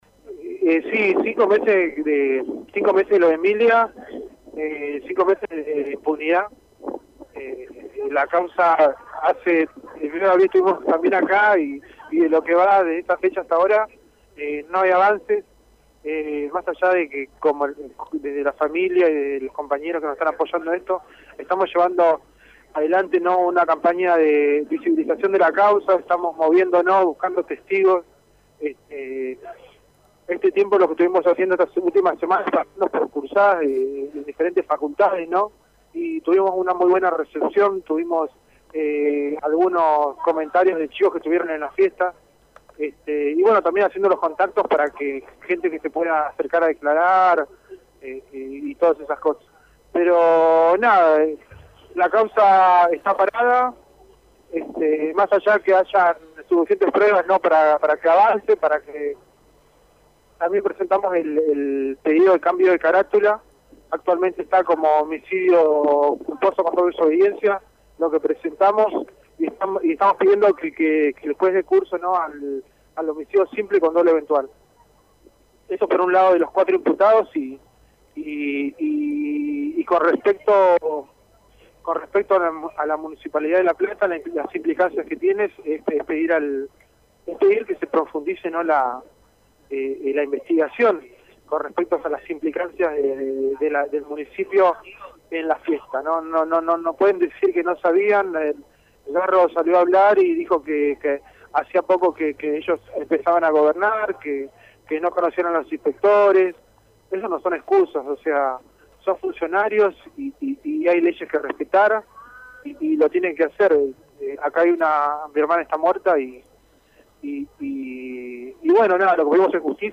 Movilización a la fiscalia